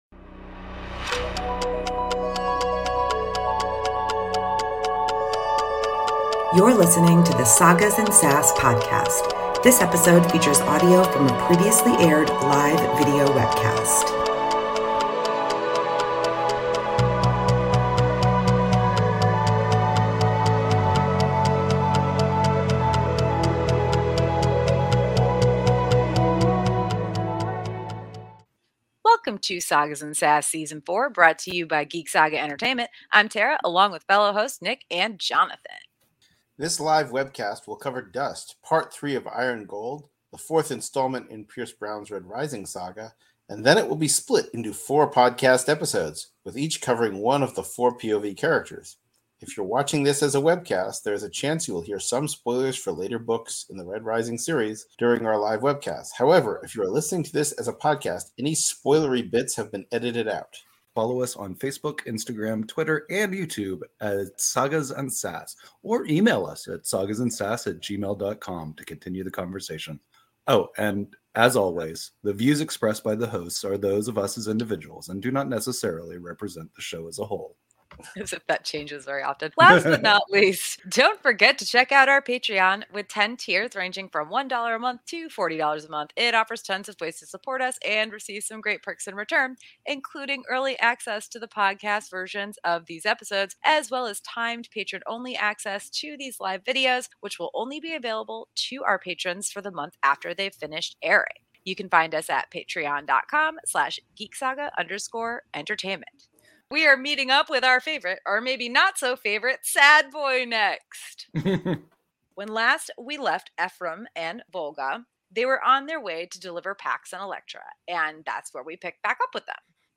Episode 65 of the Sagas & Sass Podcast originally aired as a live webcast on April 5, 2023.